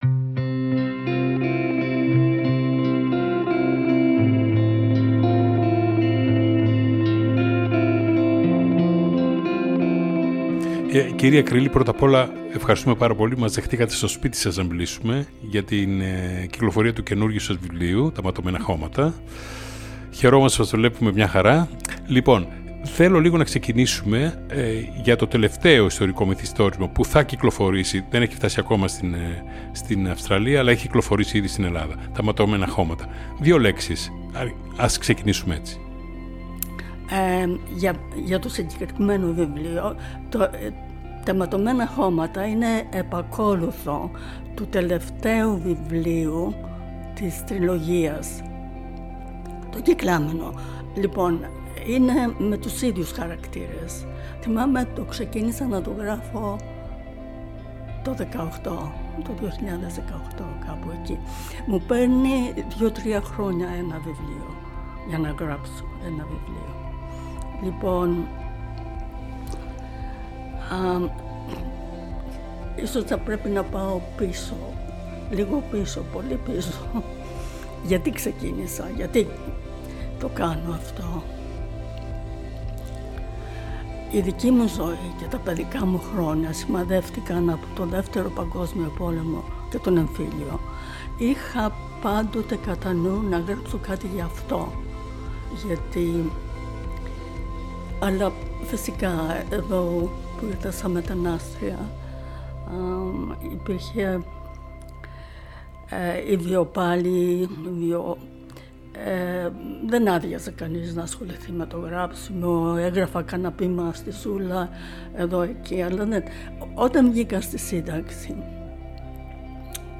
συνέντευξη
Νυκτερινοί Περίπατοι”, του ελληνόφωνου ραδιοφώνου του Σίδνει